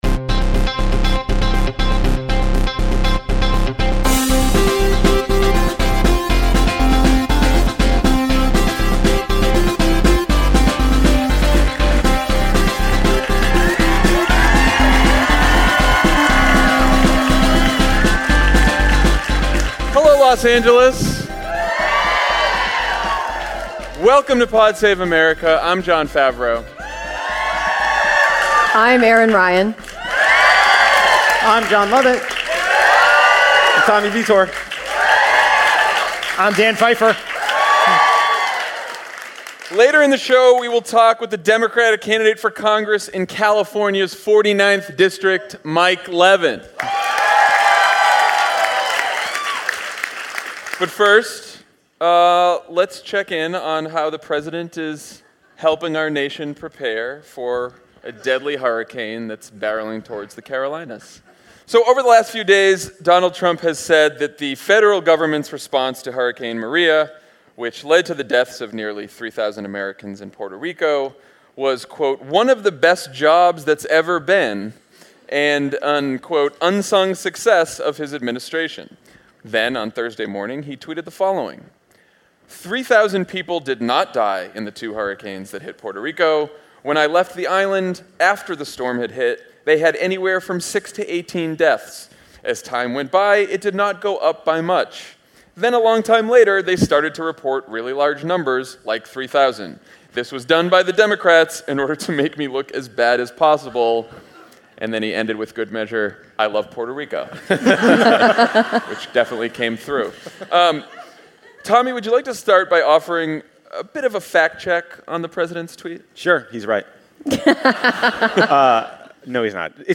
“The hurricane truther.” (LIVE in LA)
Trump prepares for Hurricane Florence by denying the deaths caused by Hurricane Maria, and Democrats spend the final months of the midterms talking health care. Then Mike Levin, the Democratic candidate in the CA 49th, joins Jon, Jon, Tommy, Dan, and Erin Ryan on stage at the El Rey theater in Los Angeles.